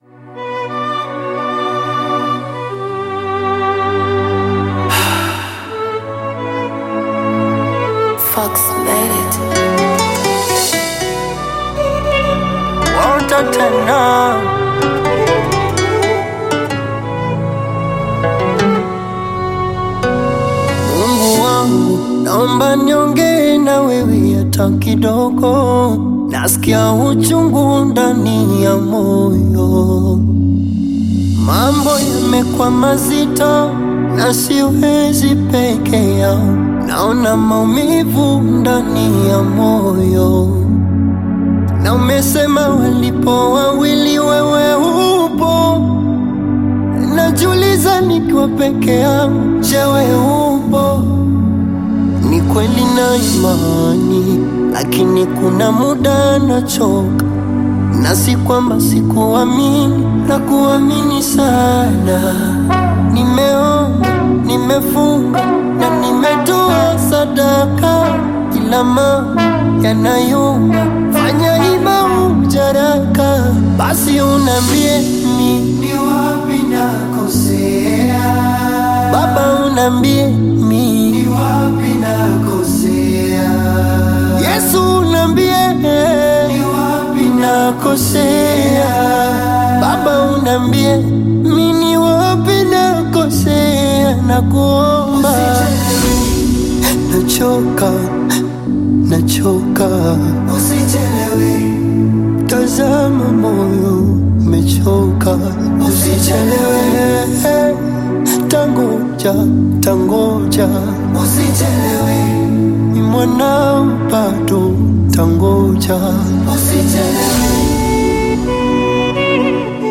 Gospel music
Gospel song